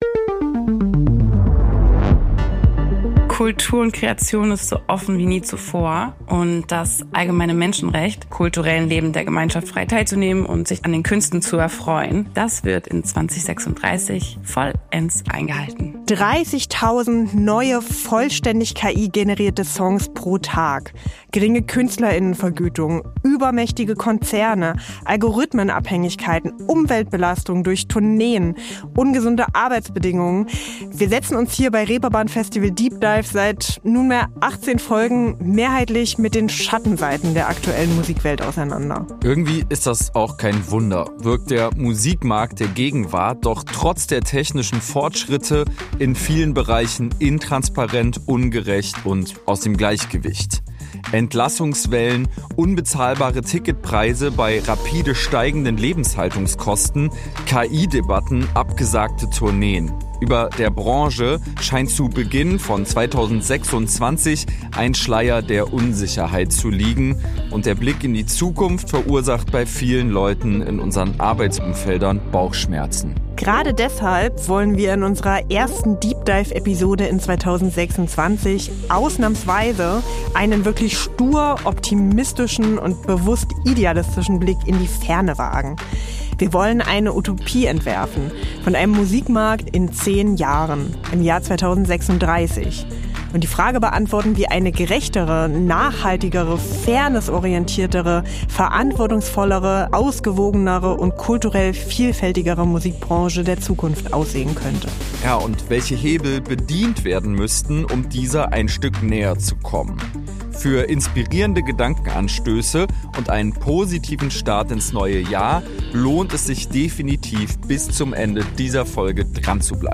Notiz zur Transparenz: Ein kurzer Teil dieser Episode wurde mithilfe von KI erstellt, dieser wird auch anmoderiert und soll als Fallbeispiel dienen (ab Minute 49:53).